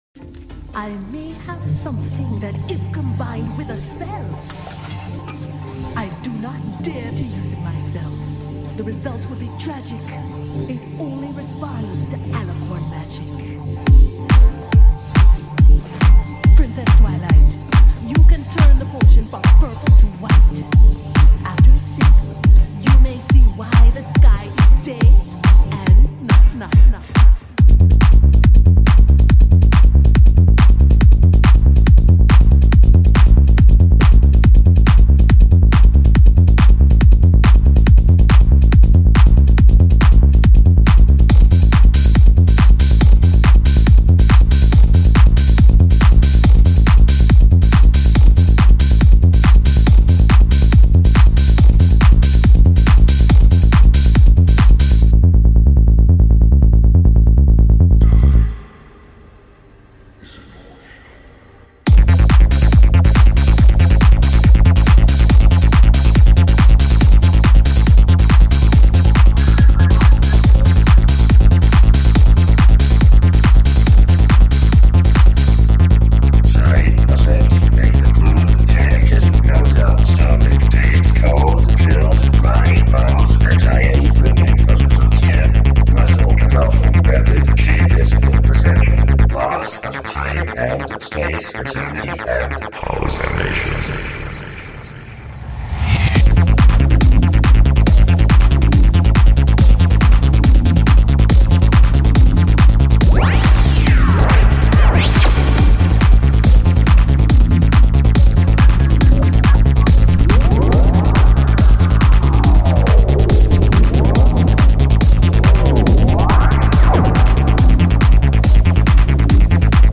Want more dark and strange "music"?